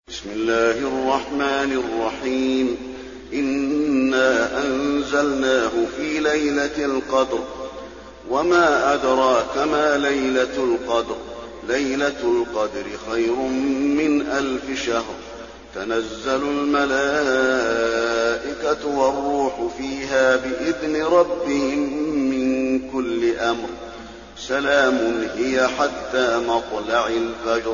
المكان: المسجد النبوي القدر